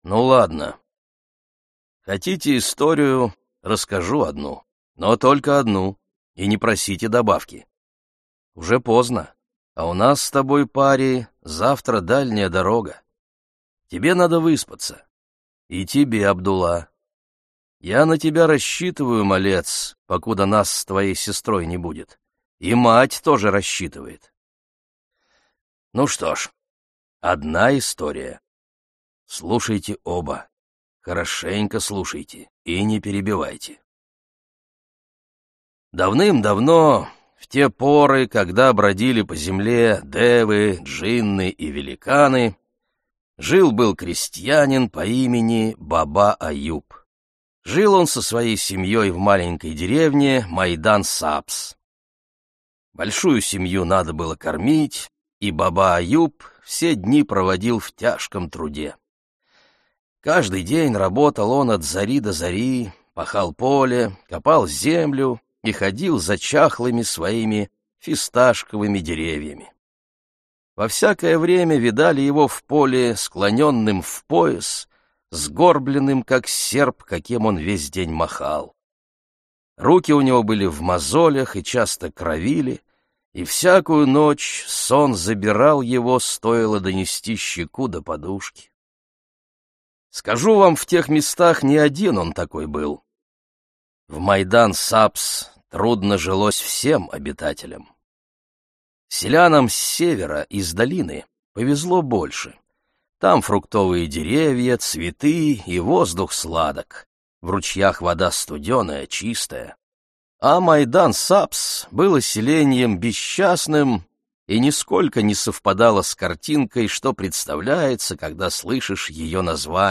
Аудиокнига И эхо летит по горам - купить, скачать и слушать онлайн | КнигоПоиск